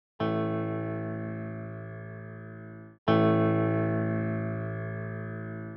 Взял сэмпл фоно и электрогитары. Сначала смикшировал с атаками в стерео и моно, затем без атак.